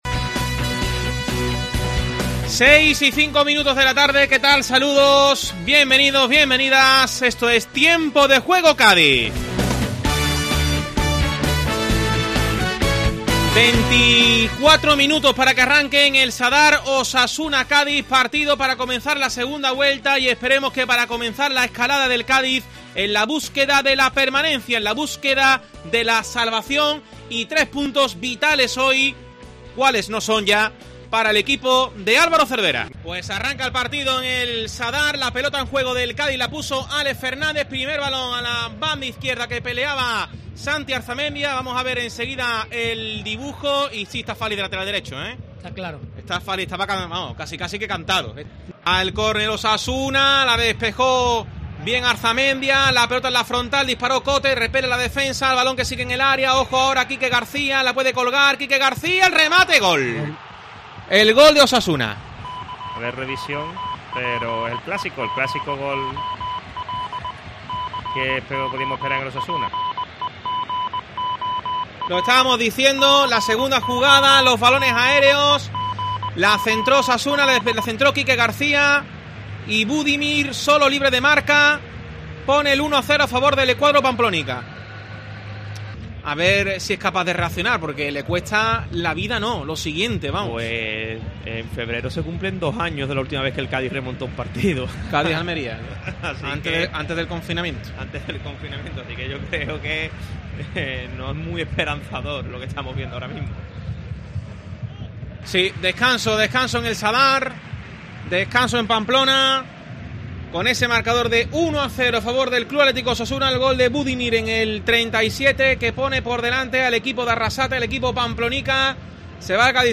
Resumen sonoro Osasuna - Cádiz